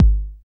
KIK 909 K.wav